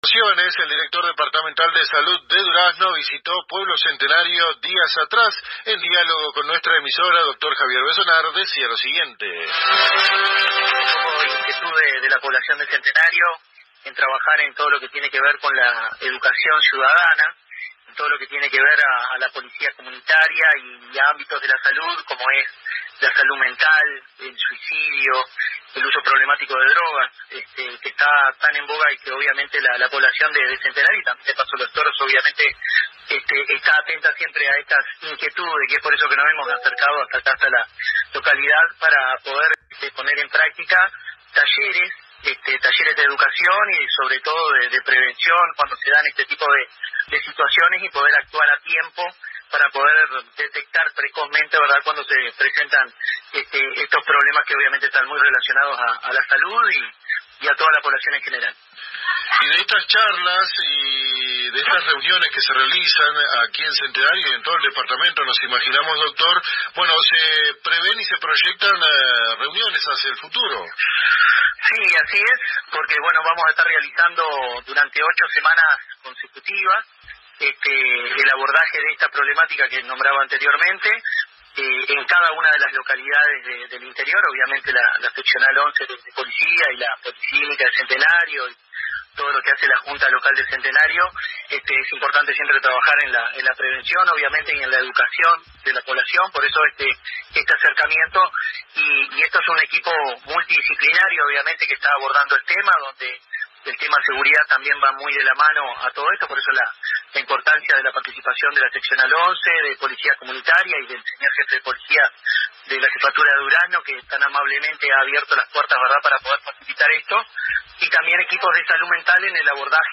El Director Departamental de Salud de Durazno conversó con la AM 1110 de nuestra ciudad acerca de su reciente visita a la vecina localidad de Pueblo Centenario, para anunciar la pronta implementación de charlas de educación ciudadana dirigidas a los vecinos de dicha población, como una forma de contribuir a la detección y solución de diversos problemas comunitarios desde una óptica interinstitucional que involucra a la Intendencia, Junta Local, Policlínico y Comisaría 11°.